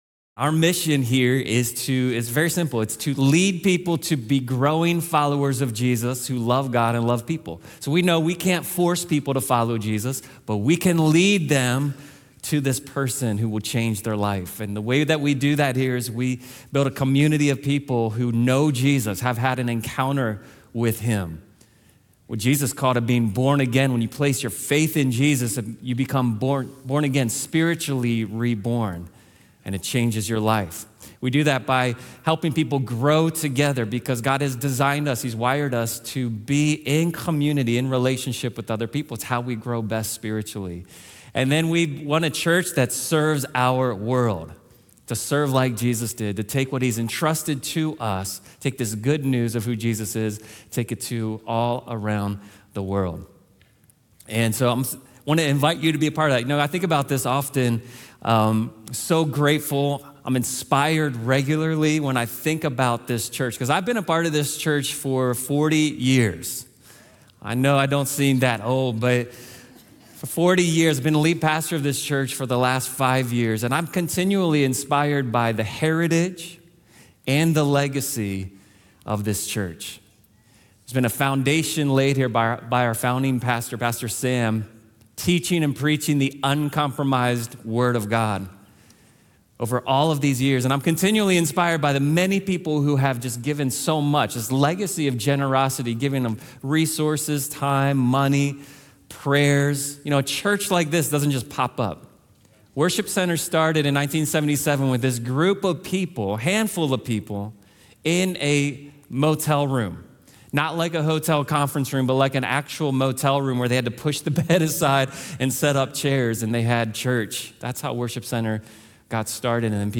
Join us for a special service focused on giving thanks for what God has done in our church throughout 2022. This service includes vision-casting for where God is taking our church next with details about our upcoming building renovations.